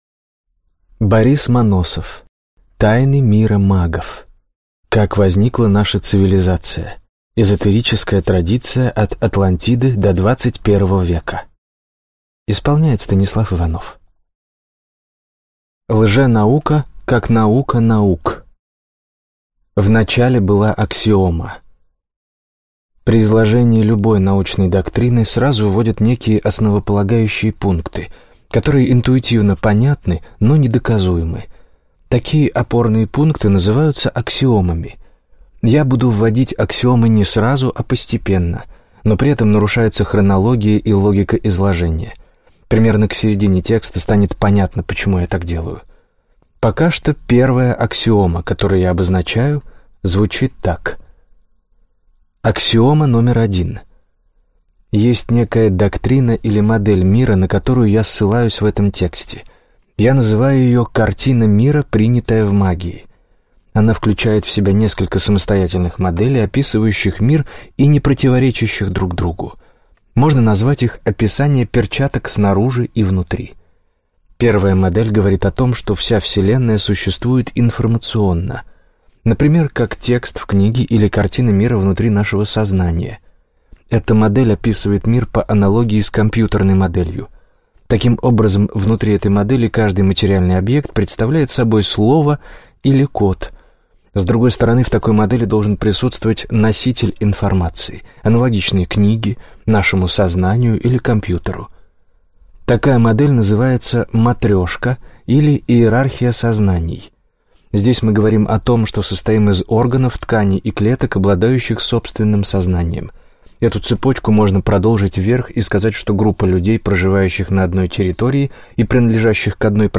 Аудиокнига Большая энциклопедия мага. Полное собрание магических знаний и ритуалов. Тайны цивилизаций, точка сборки, подчинение реальности | Библиотека аудиокниг